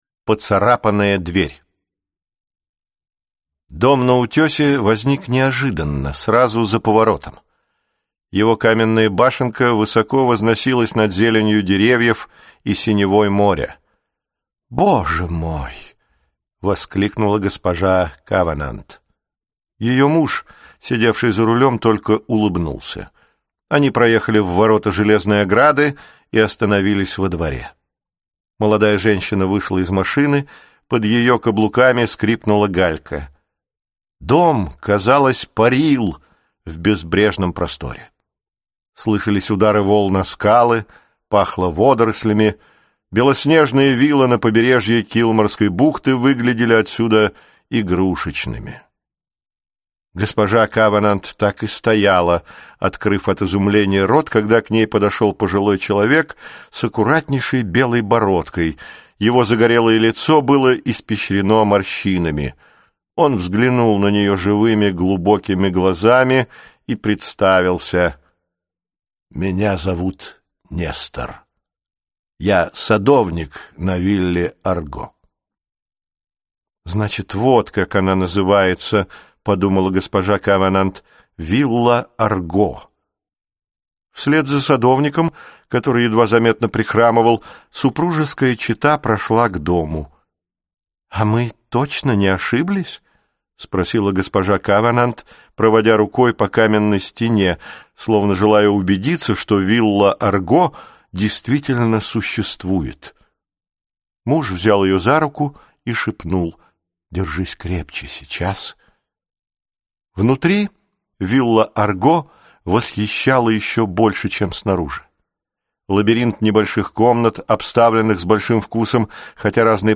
Аудиокнига Ключи от времени | Библиотека аудиокниг
Прослушать и бесплатно скачать фрагмент аудиокниги